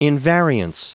Prononciation du mot invariance en anglais (fichier audio)
Prononciation du mot : invariance